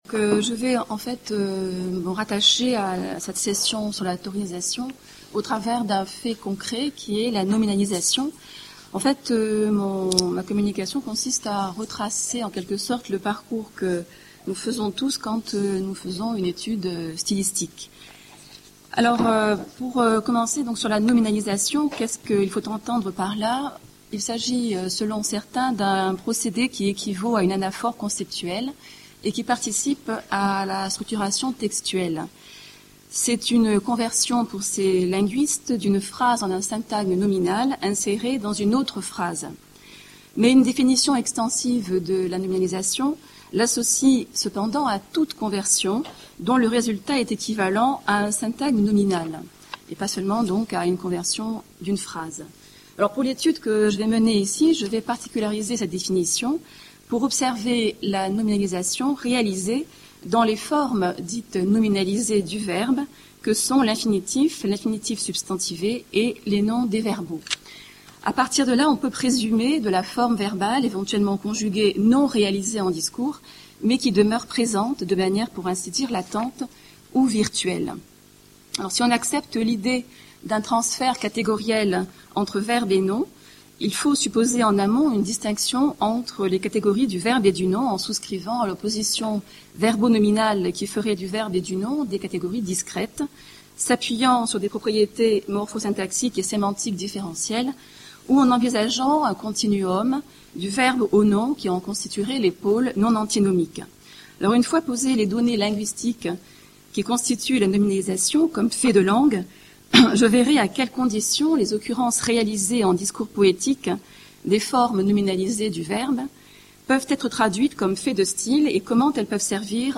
Cette communication entend s'interroger sur une des questions posées par le colloque : [comment un fait de langue peut-il devenir fait de style ?]. La question sera examinée à partir d'un corpus d'œuvres poétiques du XIXe siècle et d'un faisceau de faits de langue qu'on peut regrouper sous la dénomination de nominalisation du verbe réalisée par les modes impersonnels et les substantifs à base verbale.